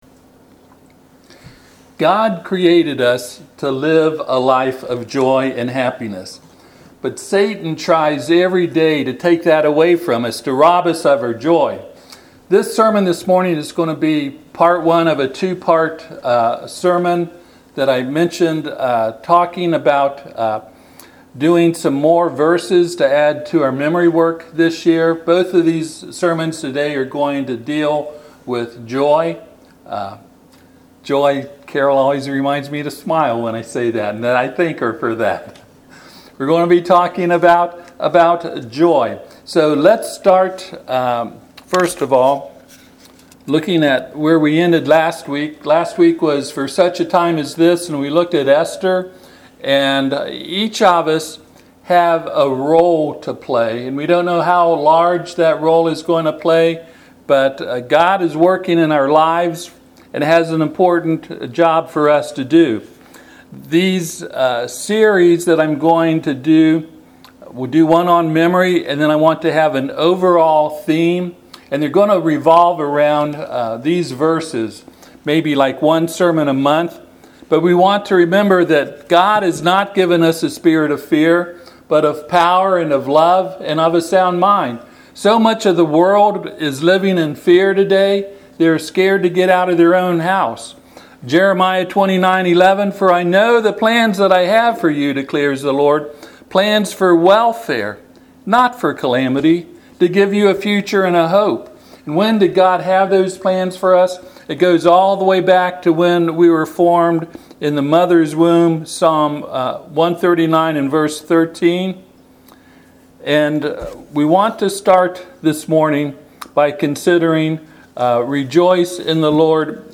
This sermon is part one of a two part sermon to help us firmly instill true joy in our lives and help us to demonstrate in our lives the joy of Christ.
Philippians 4:4-9 Service Type: Sunday AM God created us to live a life of joy and happiness.